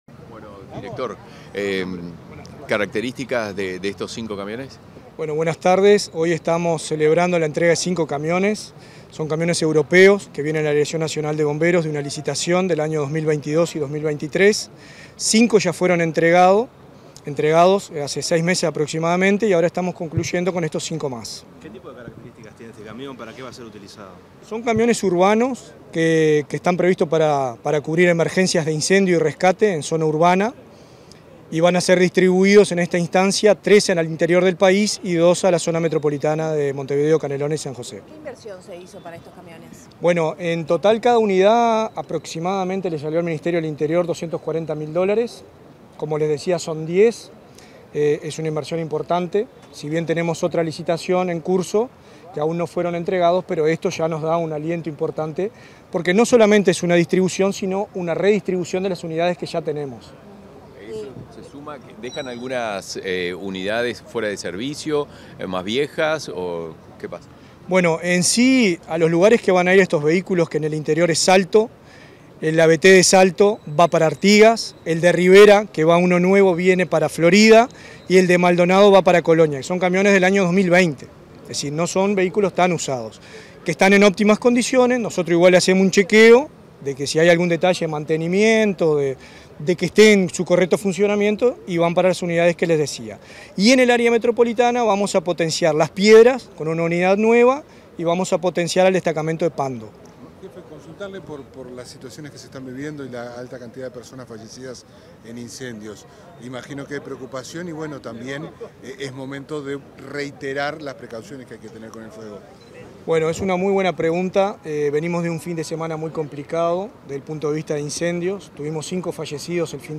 Declaraciones del director nacional de Bomberos, Richard Barboza
Declaraciones del director nacional de Bomberos, Richard Barboza 18/06/2024 Compartir Facebook X Copiar enlace WhatsApp LinkedIn Tras la entrega de cinco autobombas por parte del Ministerio del Interior a la Dirección Nacional de Bomberos, el 18 de junio, el director de la entidad beneficiada, Richard Barboza, dialogó con la prensa.